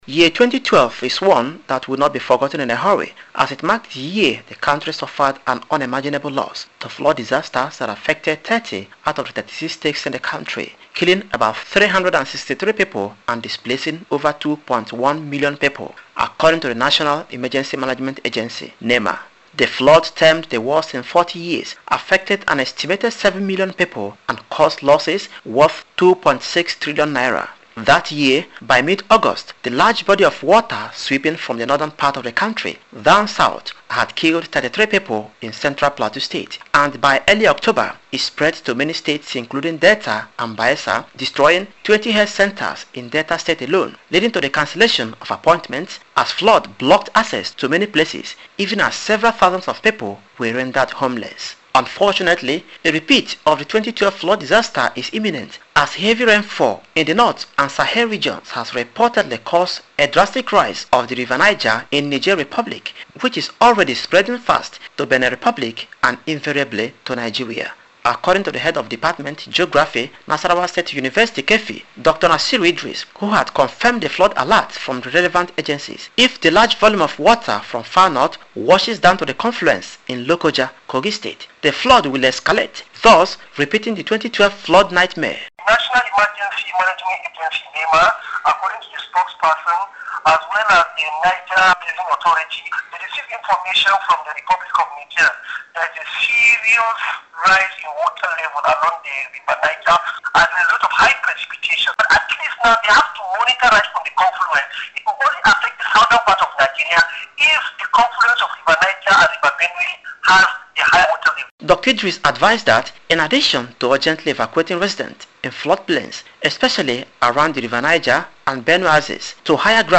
Radio Reports
FLOOD-ALERT-EMERGENCY-REPORT.mp3